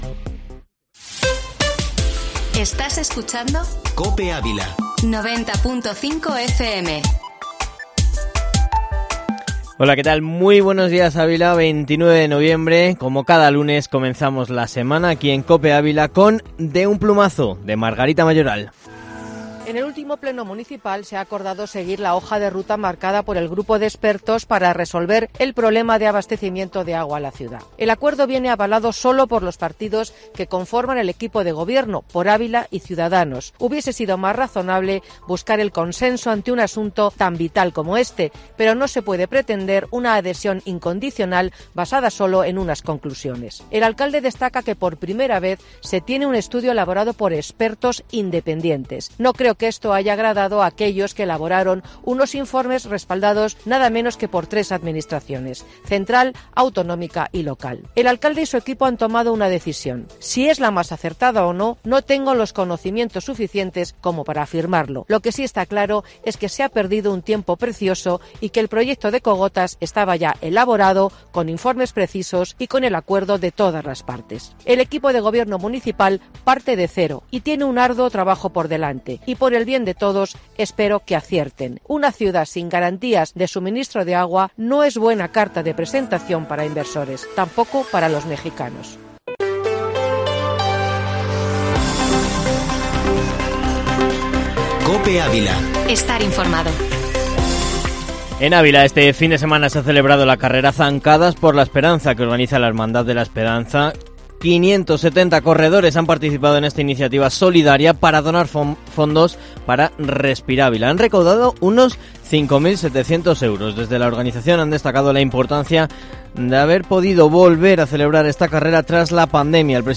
Informativo Matinal Herrera en COPE Ávila -29-nov